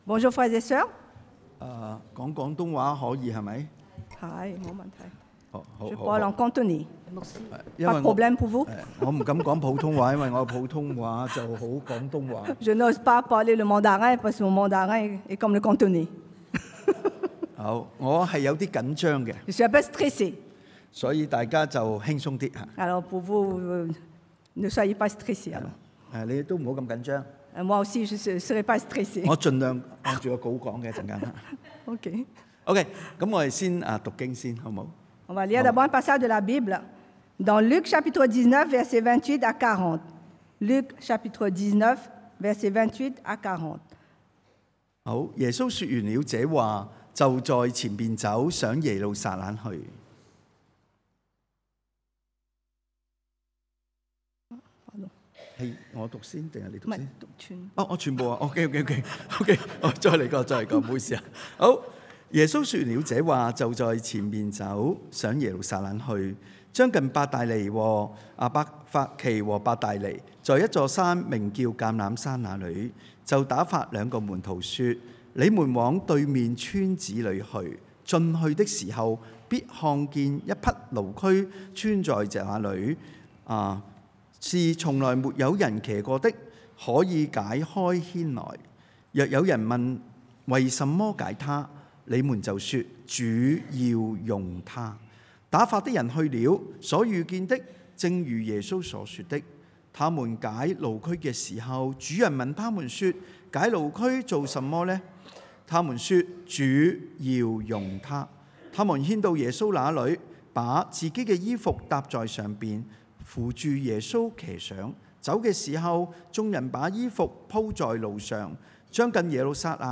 Passage: Luc 路加福音 19 :28-40 Type De Service: Predication du dimanche « Vrais ou faux chrétiens ?